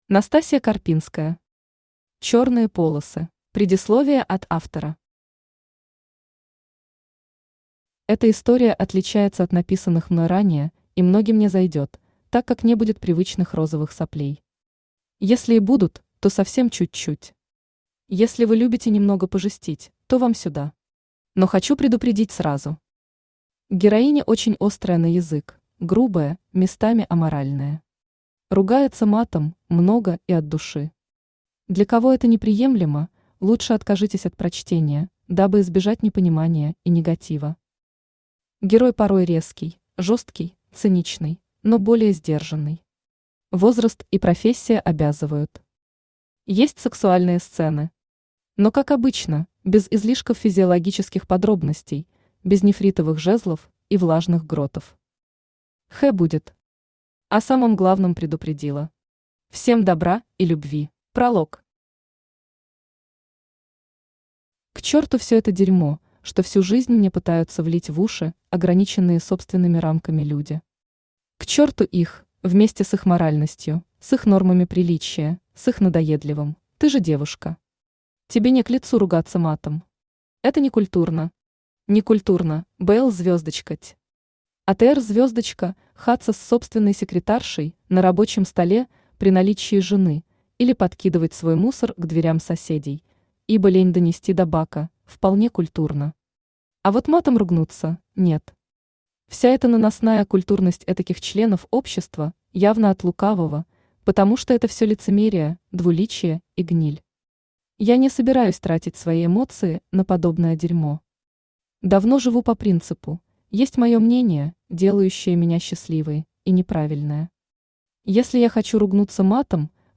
Аудиокнига Черные полосы | Библиотека аудиокниг
Aудиокнига Черные полосы Автор Настасья Карпинская Читает аудиокнигу Авточтец ЛитРес.